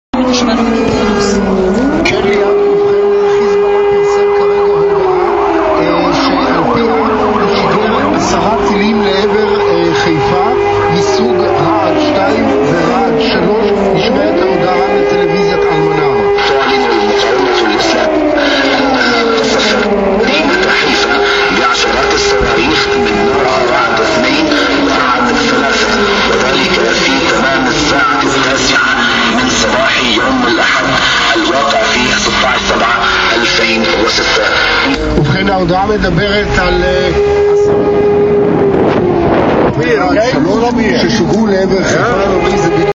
Toen we voor een stoplicht stonden, klonk het luchtalarm opnieuw.
Met loeiende sirenes reden zij in noordelijke richting van Israëls havenstad.
1-haifa-luchtlarm-ambulances-en-radio-kol-yisrael.mp3